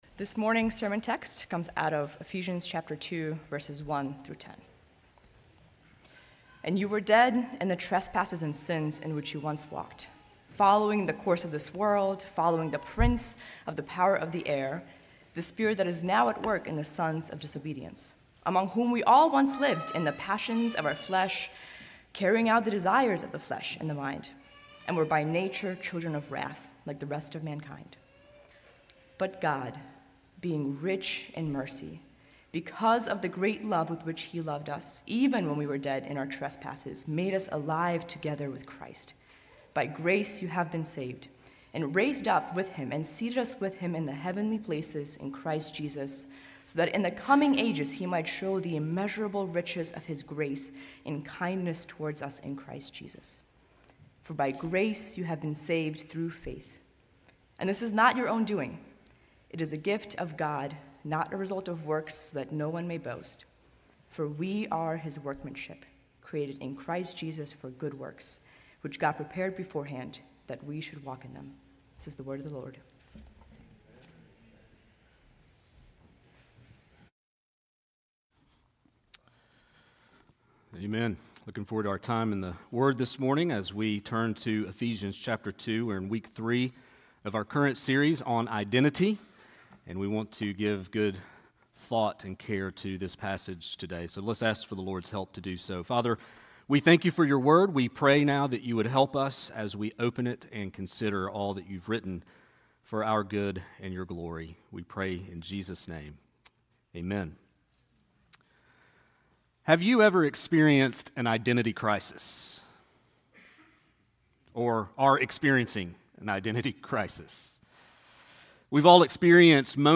sermon8.17.25.mp3